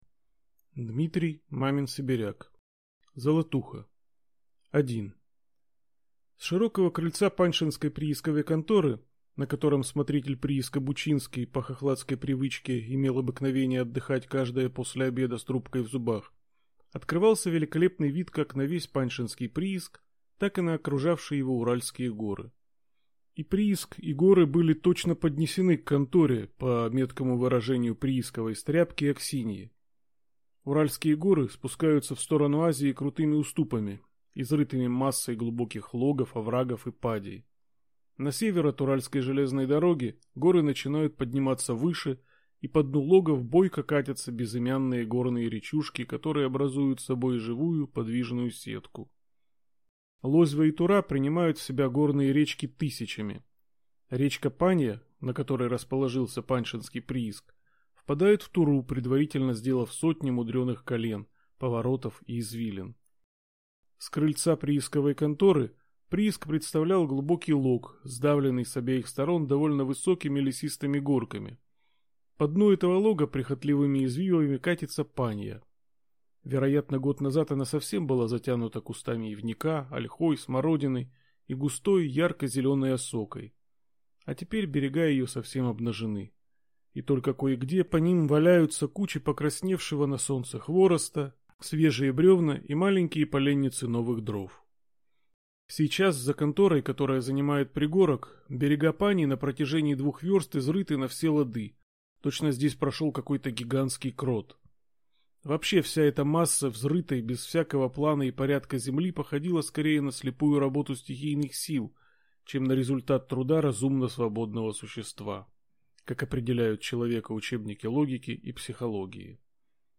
Аудиокнига Золотуха | Библиотека аудиокниг